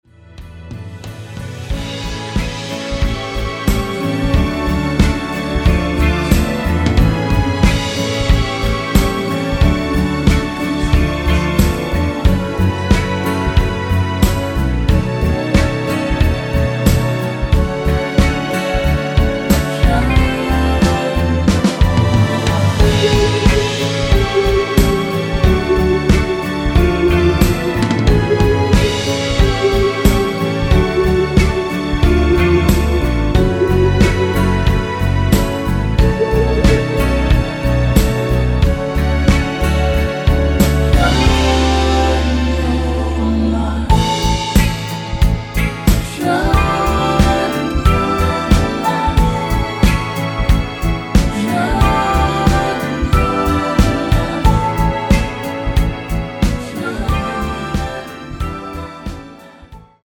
(-1) 내린 코러스 포함된 MR 입니다.(미리듣기 참조)
◈ 곡명 옆 (-1)은 반음 내림, (+1)은 반음 올림 입니다.
앞부분30초, 뒷부분30초씩 편집해서 올려 드리고 있습니다.